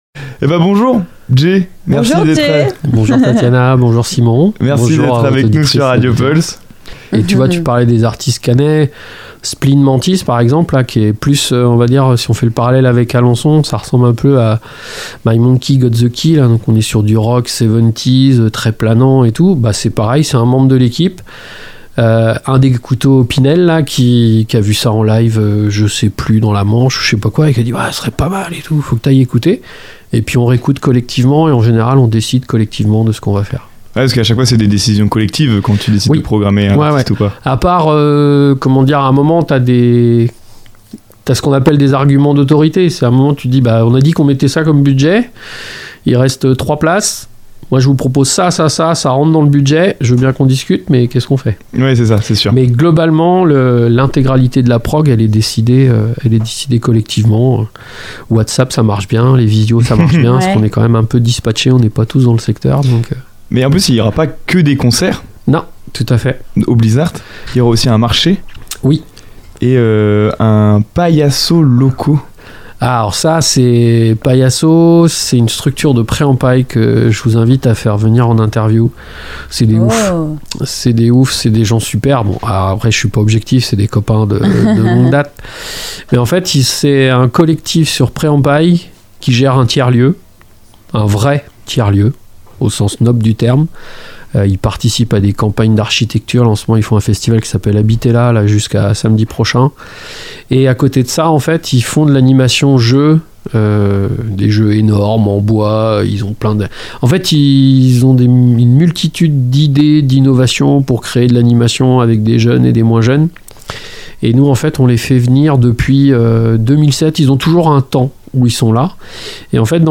Pour en savoir plus sur cet rencontre n'hésitez pas à écouter jusqu'au bout l'interview, et pourquoi pas vous aussi, faire partie des festivaliers !